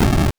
explosion_13.wav